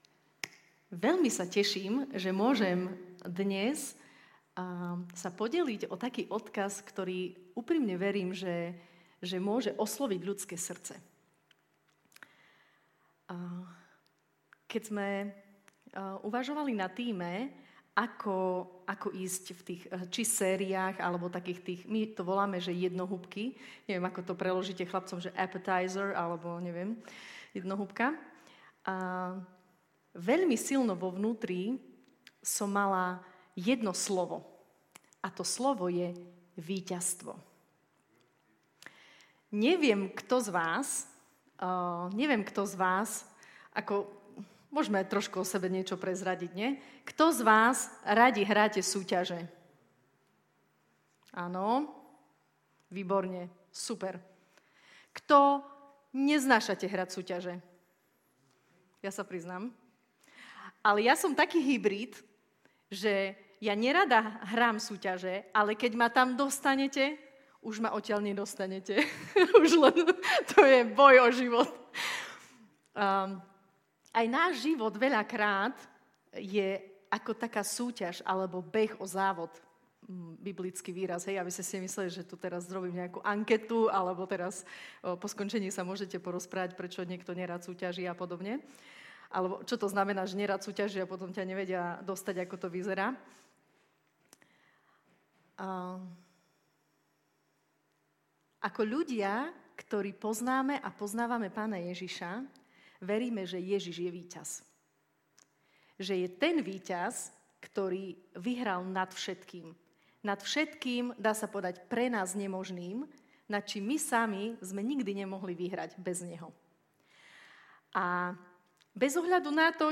Najnovšia kázeň